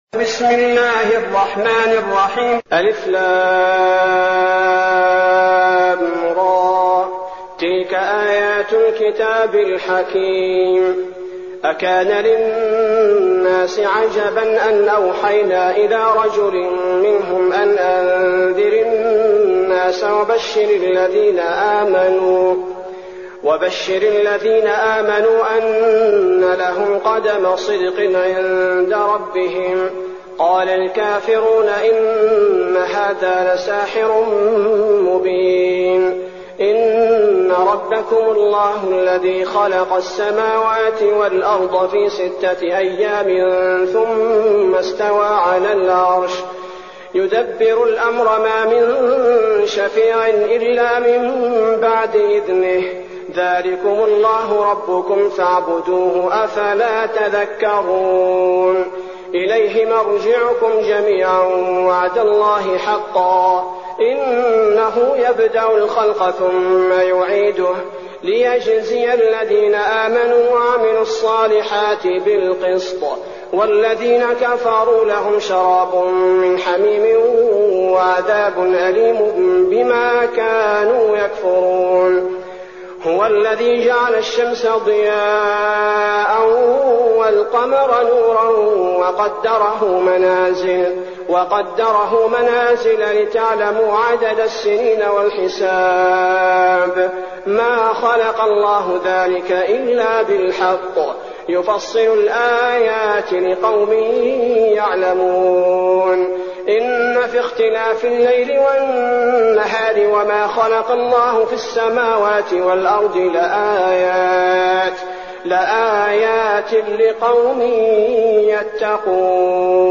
المكان: المسجد النبوي الشيخ: فضيلة الشيخ عبدالباري الثبيتي فضيلة الشيخ عبدالباري الثبيتي يونس